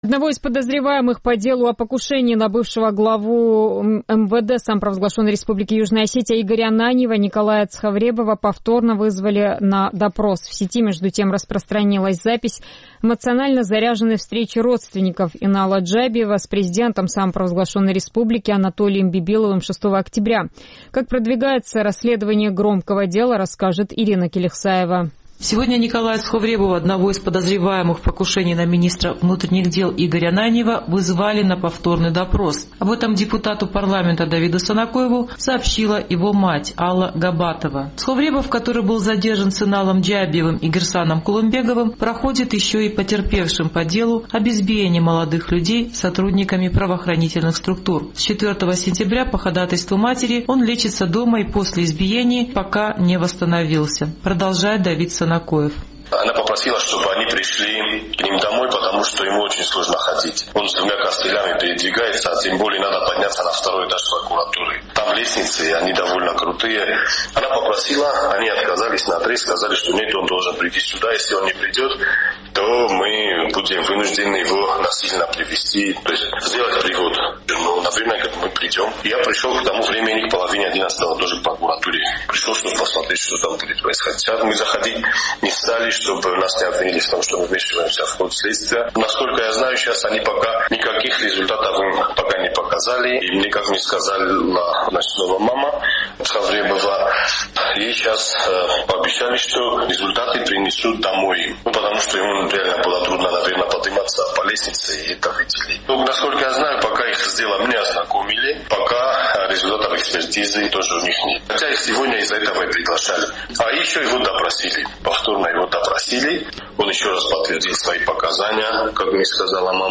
Общение с президентом в его кабинете в присутствии генерального прокурора Урузмага Джагаева выдалось крайне эмоциональным.